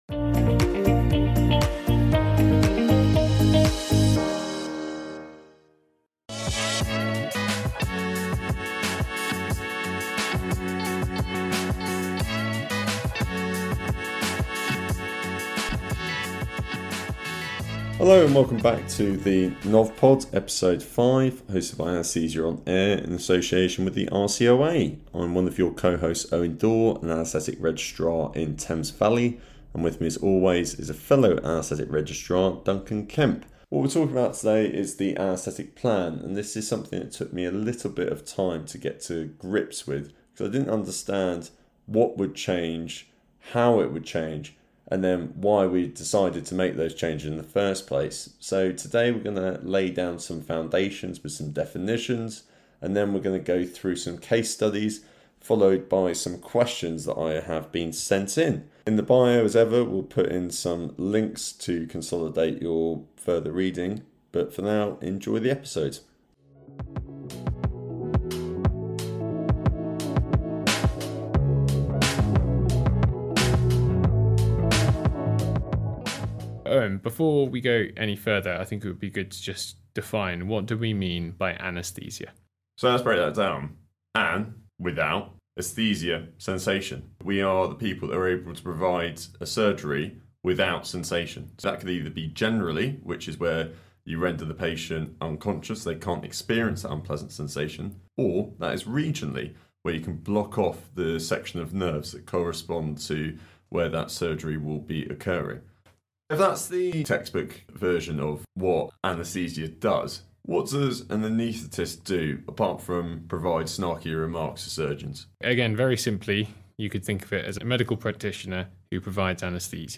We are two anaesthetic registrars on a mission to help our new anaesthetic colleagues get to know some of the new concepts you will come across in your novice period, hopefully helping to take away any anxiety around starting your time in anaesthetics.